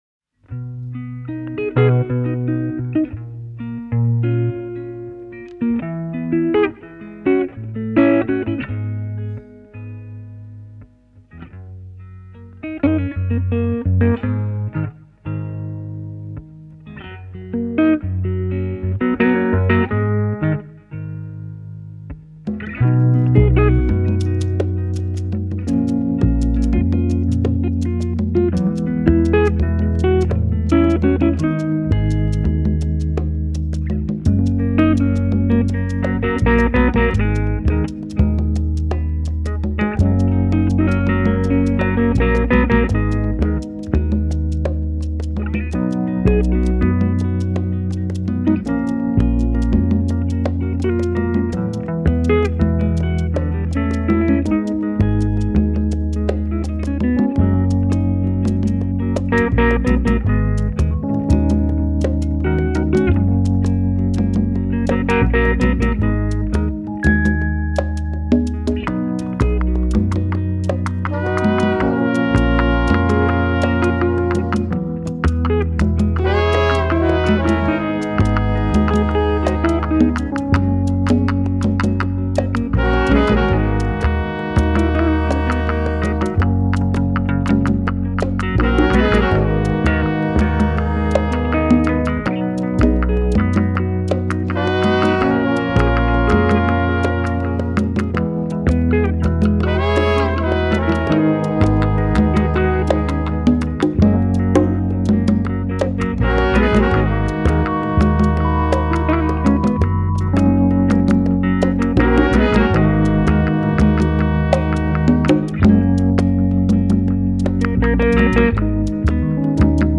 Genero: Jazz